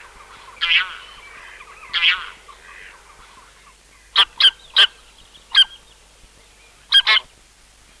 Bean Goose
Bean-Goose.mp3